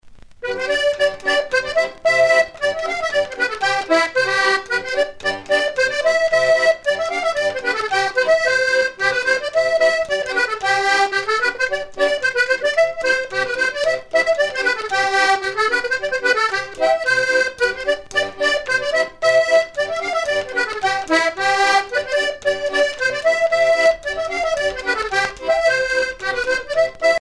Polka
danse : polka